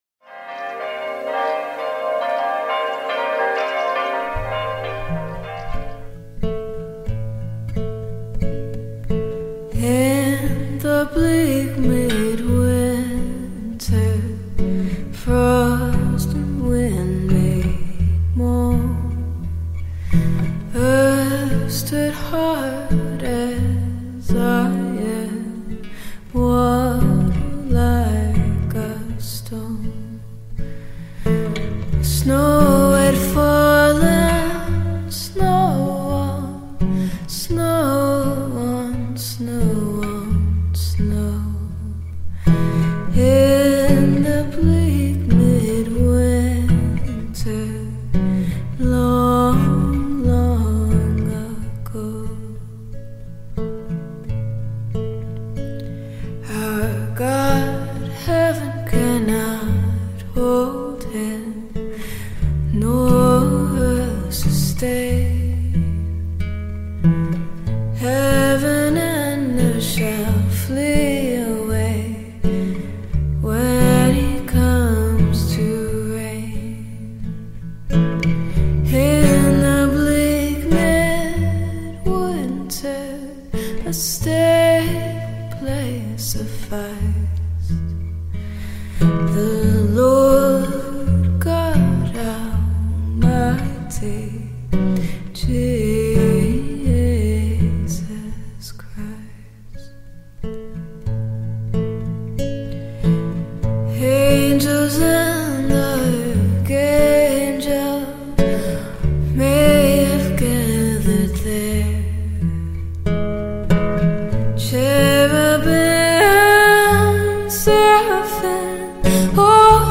liest die Lesung aus dem Evangelium nach Matthäus.
Klavier-Improvisation